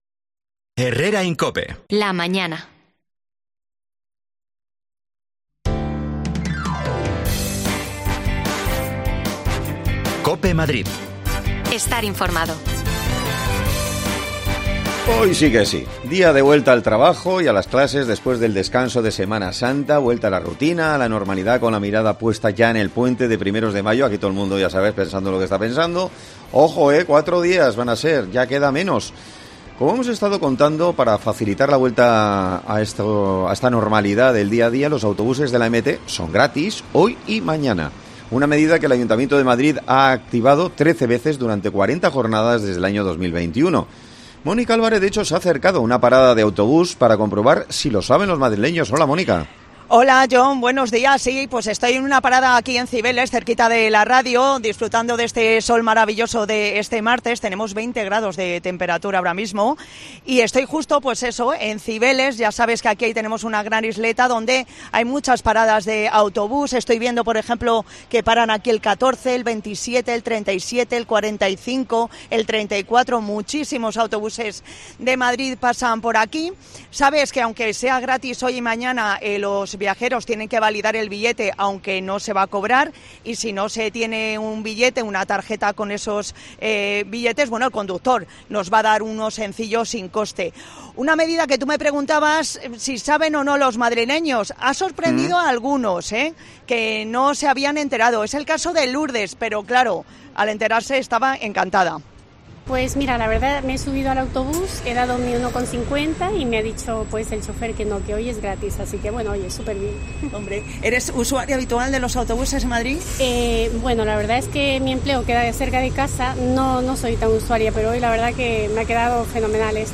AUDIO: Los autobuses de la EMT son gratis hoy y mañana por la vuelta al cole y a los trabajos... Nos bajamos a Cibeles para contarlo todo desde allí
Herrera en COPE Madrid